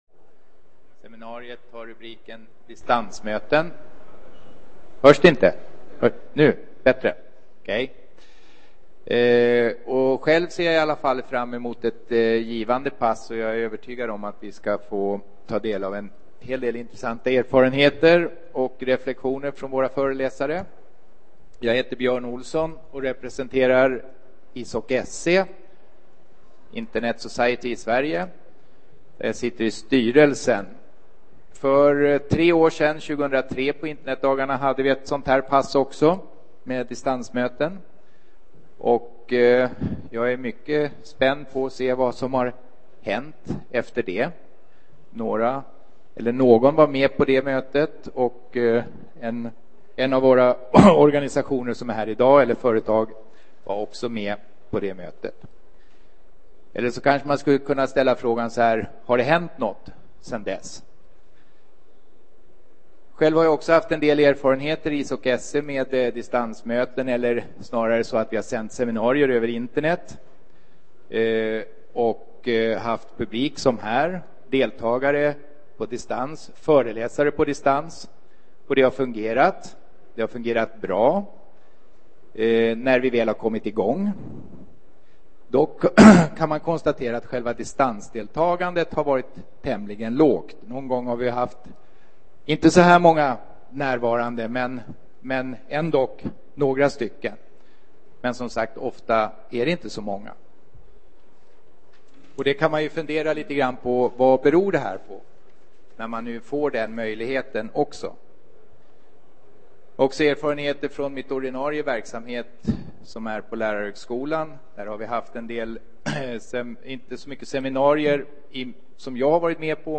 Distansm�ten blir nu snabbt en allt mer etablerad och viktig m�tesform. Under seminariet f�r vi ta del av erfarenheter fr�n n�gra organisationer som har g�tt i t�ten f�r att inf�ra och anv�nda denna m�jlighet.